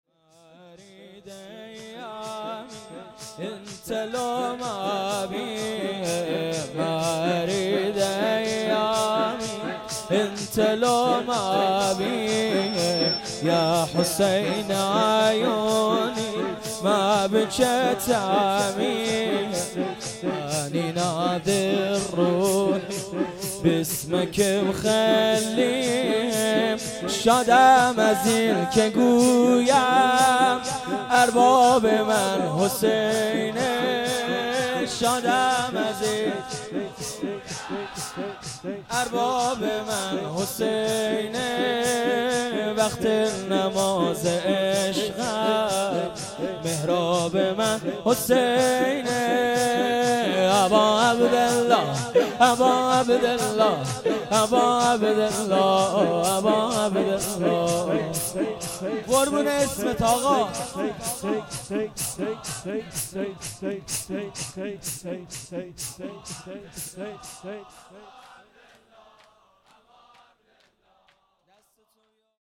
شور
شب دوم دهه‌ی دوم فاطمیه ۹۸